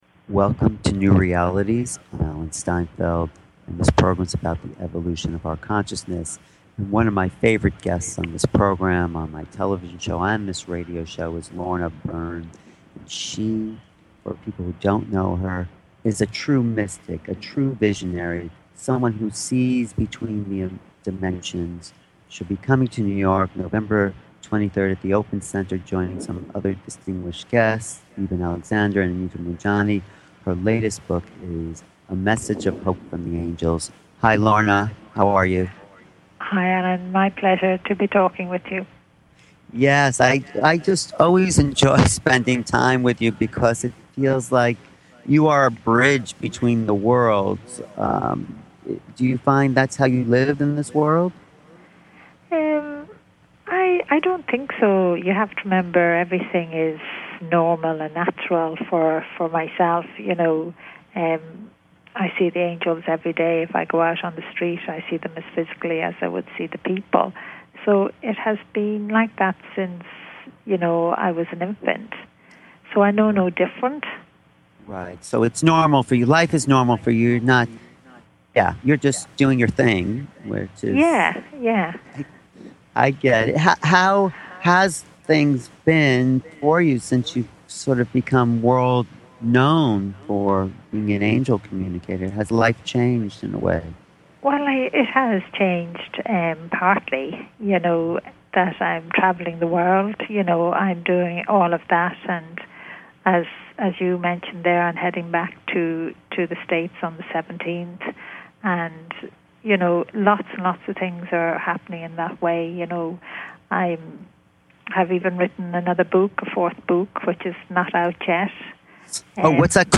Talk Show Episode, Audio Podcast, New_Realities and Lorna Byrne, Angel Seer on , show guests , about , categorized as Spiritual,Angel Communication